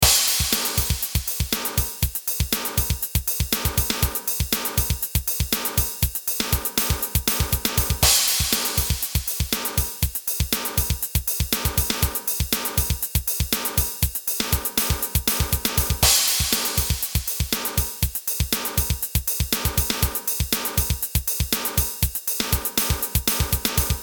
このドラムキットの着信音は、忙しい日常に刺激的なエネルギーをもたらし、呼び出しを待つ時間を楽しみに変えてくれます。
他とは一線を画す個性的なサウンドで、あなたのスマートフォンがまるで音楽のステージになったかのような感覚を味わえます。